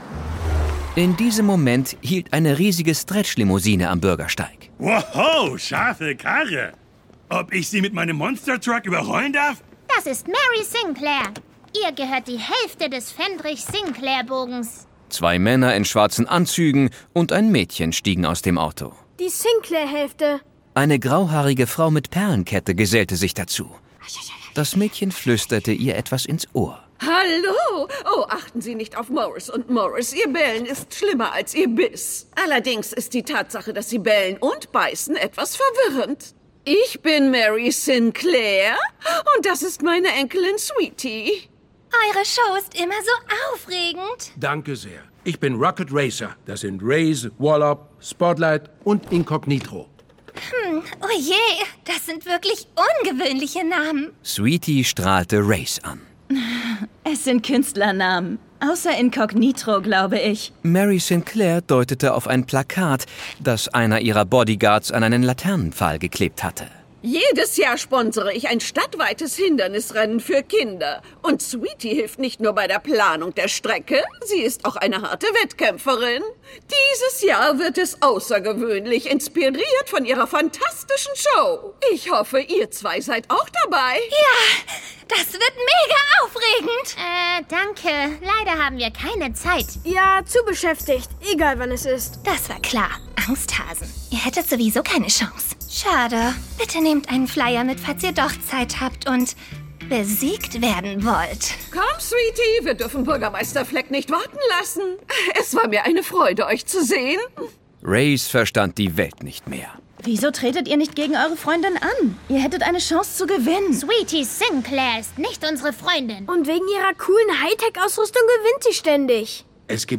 Folge 61: Das Hindernisrennen - Hörbuch